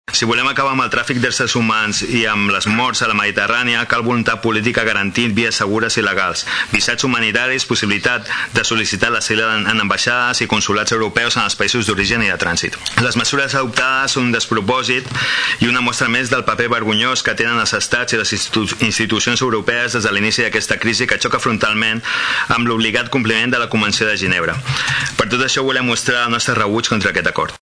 El regidor de Som Tordera, Salvador Giralt considera que el seu grup no reconeix aquesta Europa i assegura que les mesures adoptades són un despropòsit.